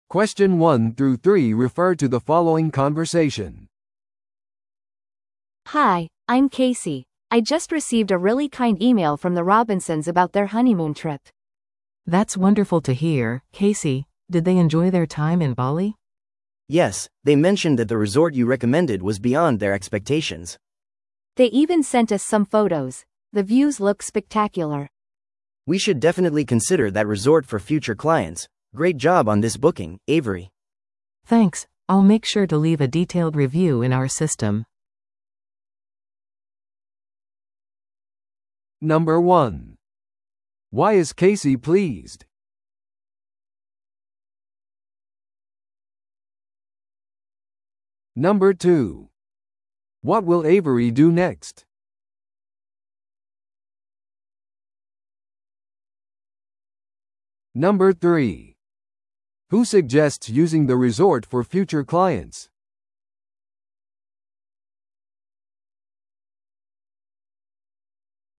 A: 会話中の女性、ケイシー。
正解 C: The man in the conversation.